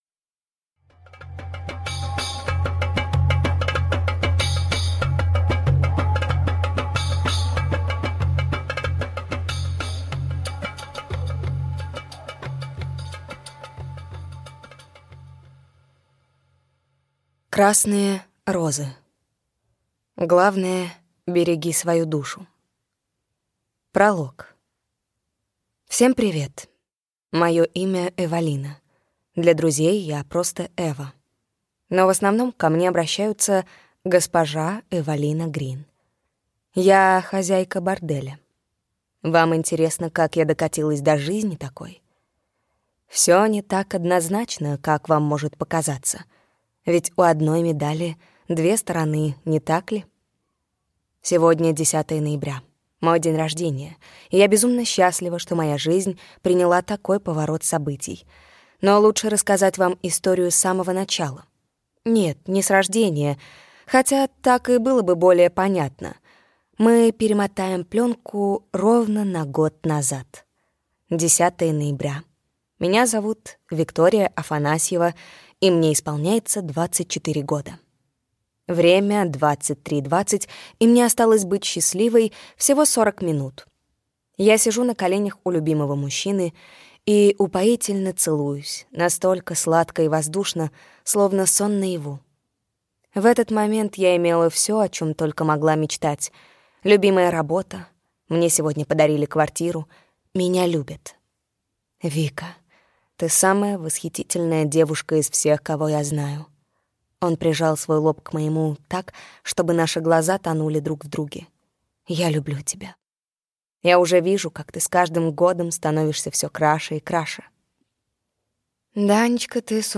Аудиокнига Красные розы | Библиотека аудиокниг